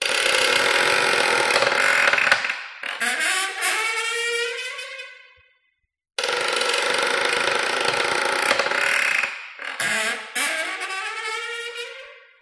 Звуки кожаных перчаток